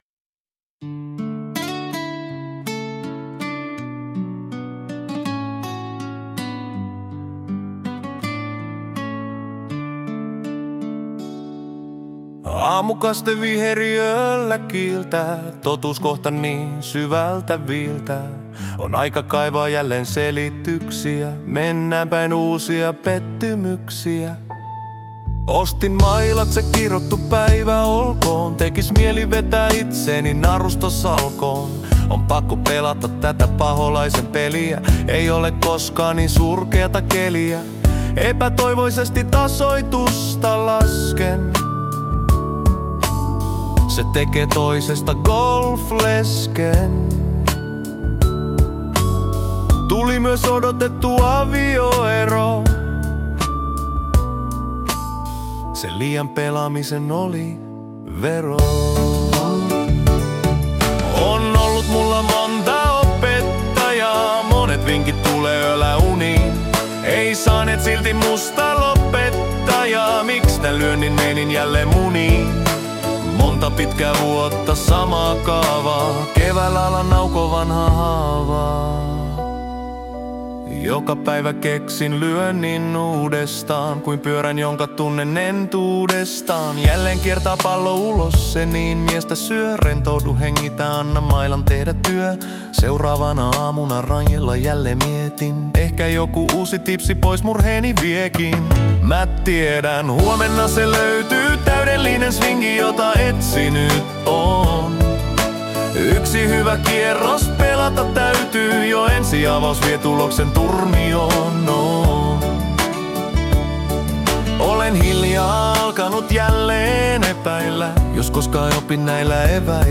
Hyvän tuulen musiikkia.